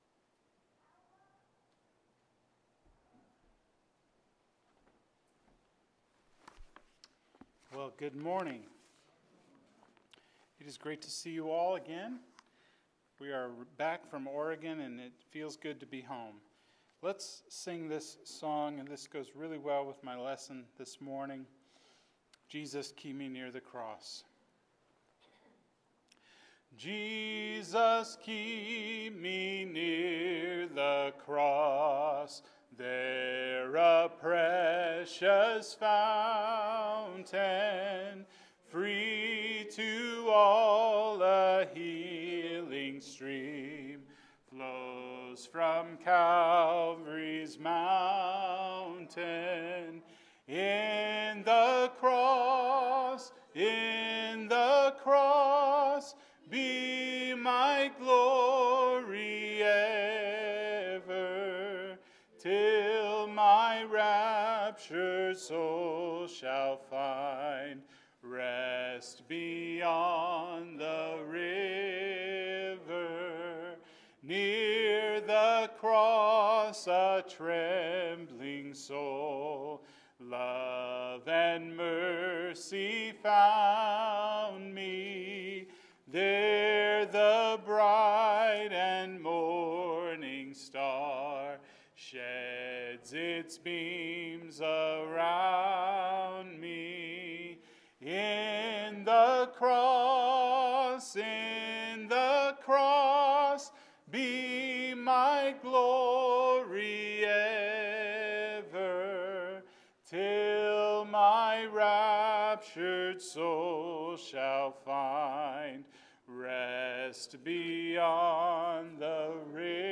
Seven – Compassion – Sermon